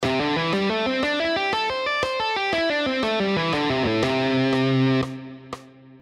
Here are the five positions of the A minor pentatonic scale, all played in triplets:
Triplet Lesson 2 – A Minor Pentatonic Position 2: